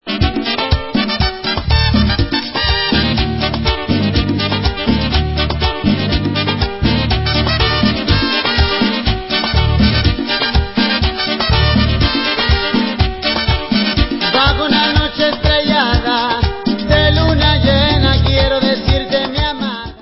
sledovat novinky v oddělení World/Latin